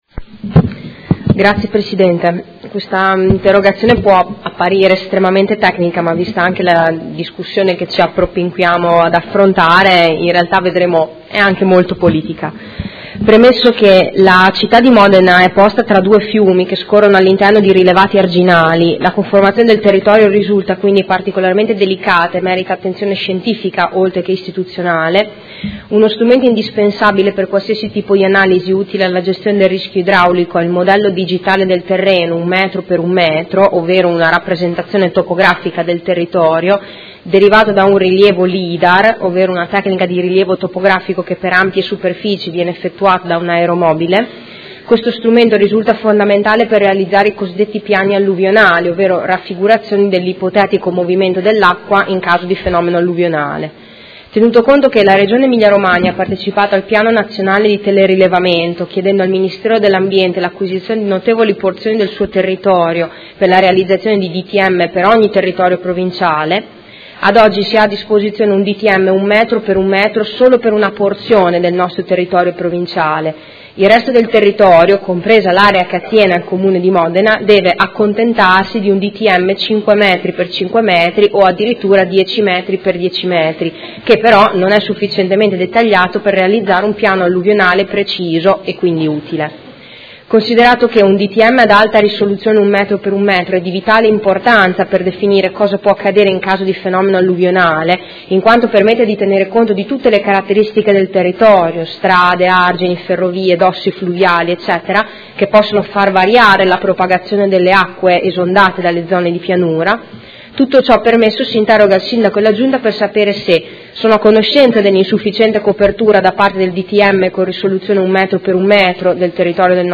Seduta del 10/03/2016. Interrogazione dei Consiglieri Morini e Stella (P.D.) avente per oggetto: Modello Digitale del Terreno provinciale e prevenzione del rischio idraulico